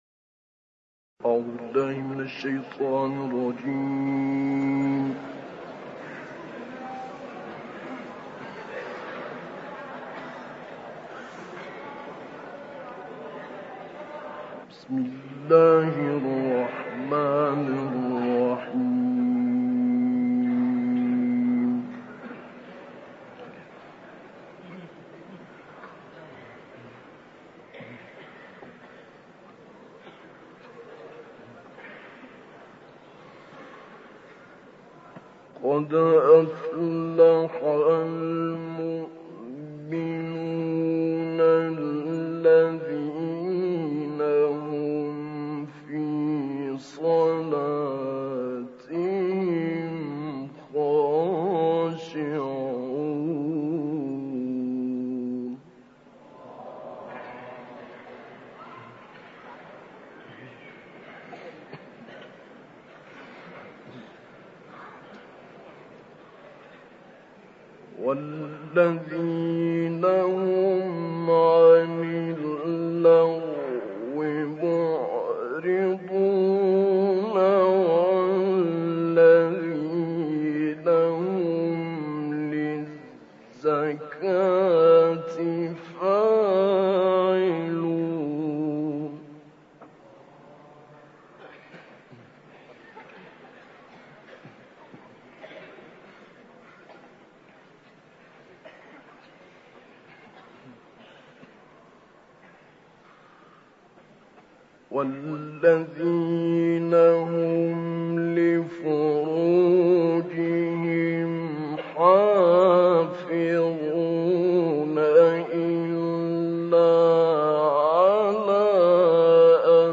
تلاوت شعیشع در مسجد اموی دمشق
گروه فعالیت‌های قرآنی: تلاوت آیاتی از کلام الله مجید با صوت ابوالعینین شعیشع که در سال 1959 میلادی در مسجد اموی دمشق اجرا شده است، ارائه می‌شود.